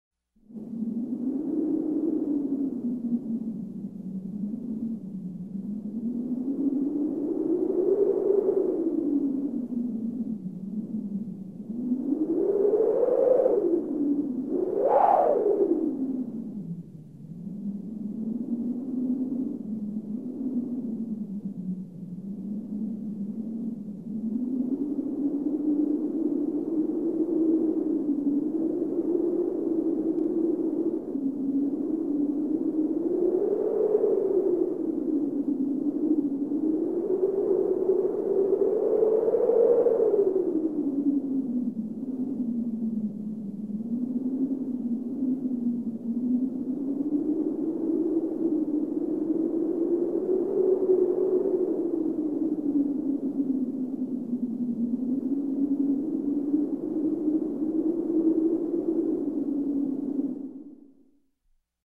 Звуки ветра
На этой странице собраны звуки ветра: от нежного шелеста листвы до мощных порывов в горах.